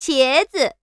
cheese_china.wav